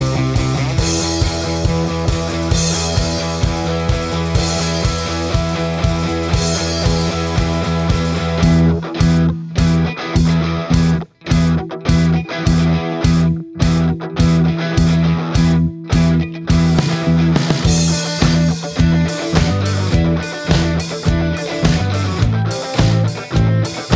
No Lead Guitar Indie / Alternative 3:57 Buy £1.50